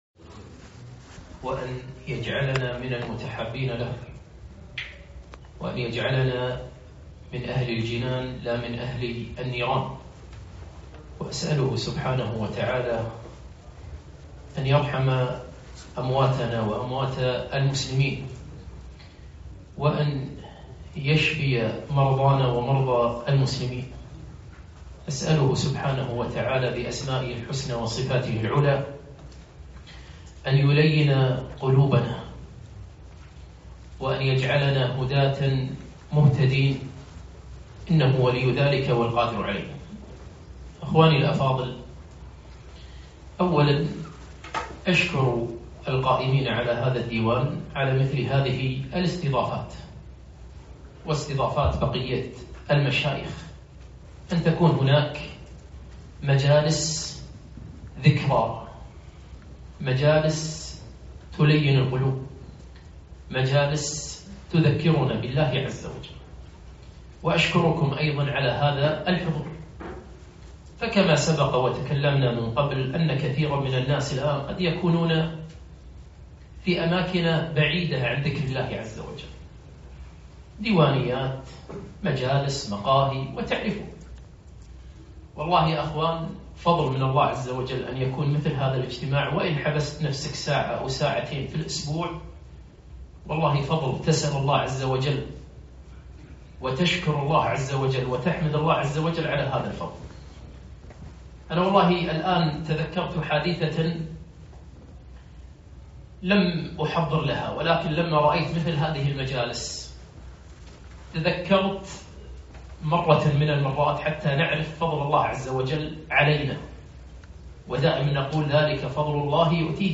محاضرة - قسوة القلب